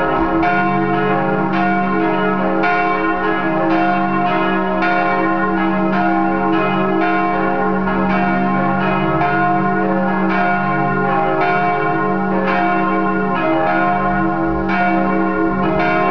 unsere Glocken  (174 kB)